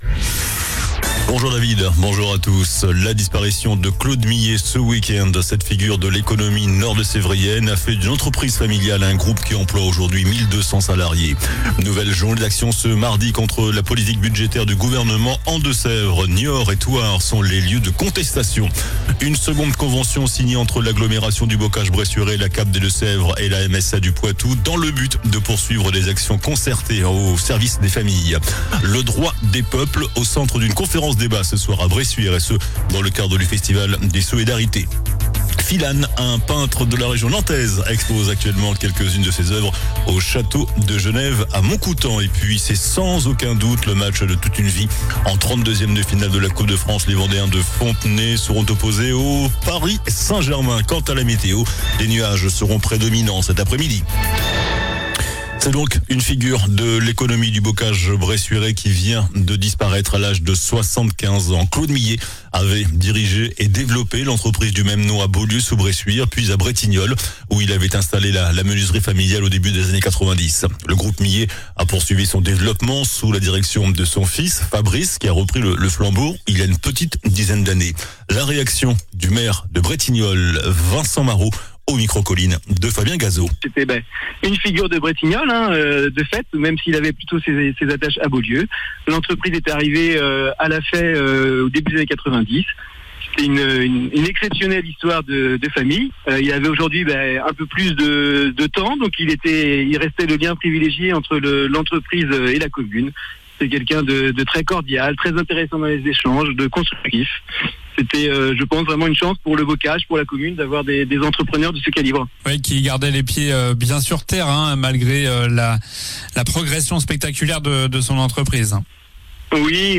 JOURNAL DU MARDI 02 DECEMBRE ( MIDI )